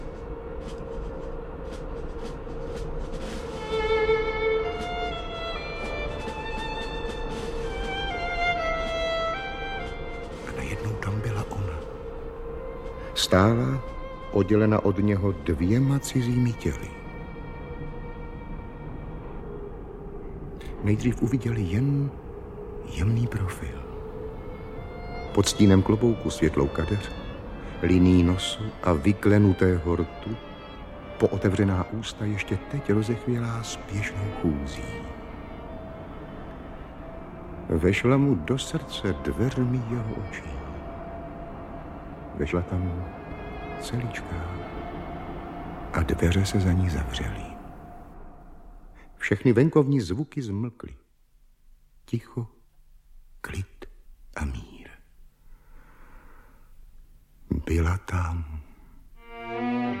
Audiobook
Read: Eduard Cupák